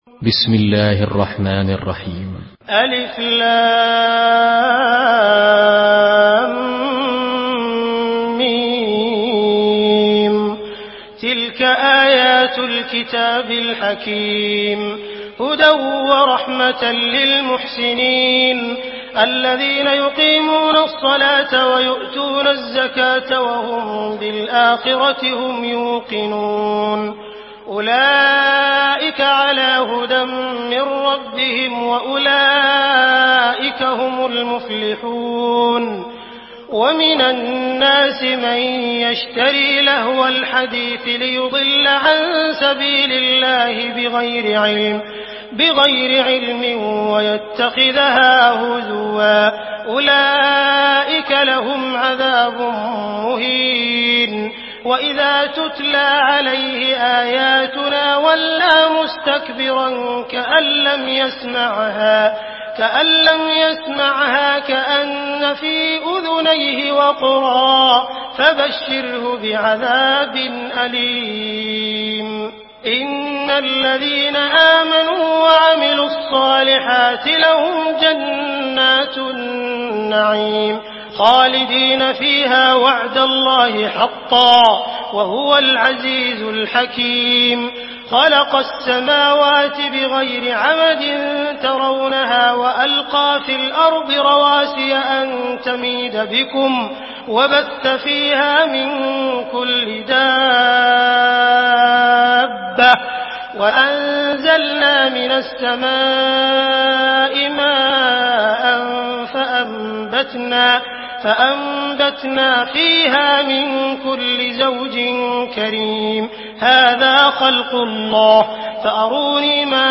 Surah لقمان MP3 by عبد الرحمن السديس in حفص عن عاصم narration.